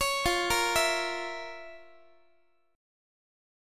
Listen to FM7sus4#5 strummed